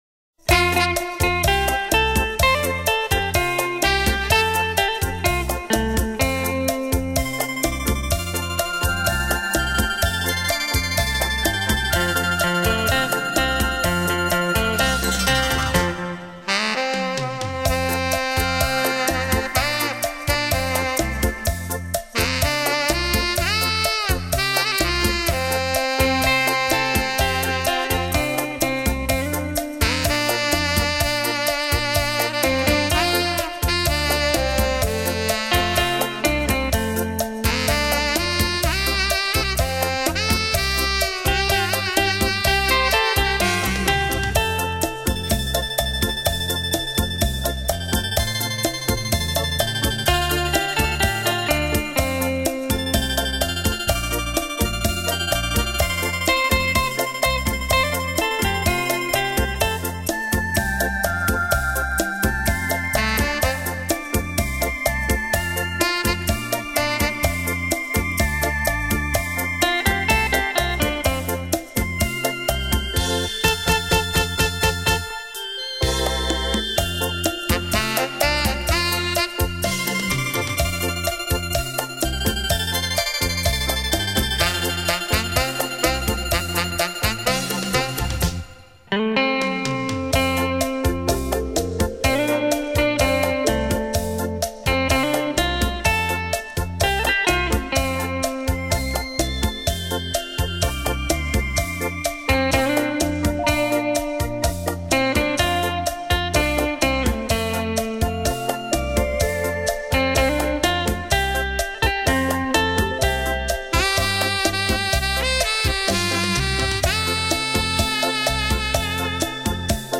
雷射版↖↗现场演奏
其创作的作品及编曲　旋律优美　节奏动听